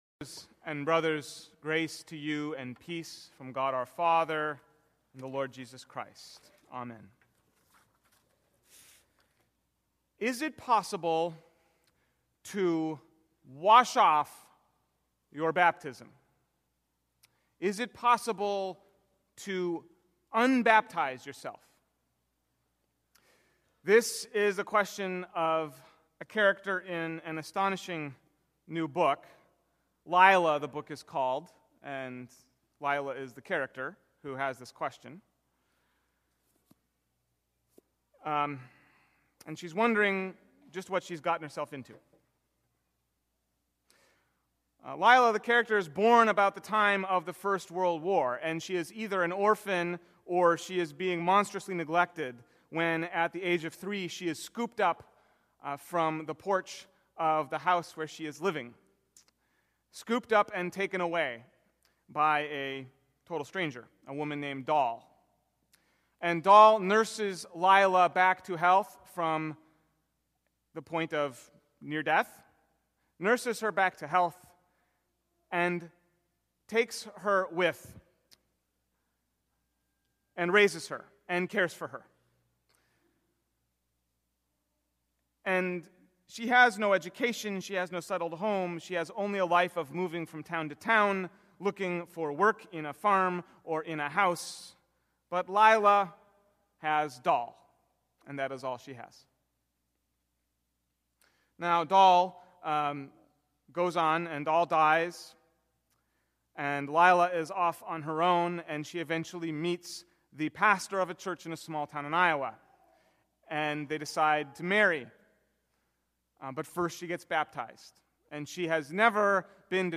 (Note: I preached this sermon on this Sunday in 2014)